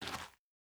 Bare Step Gravel Medium E.wav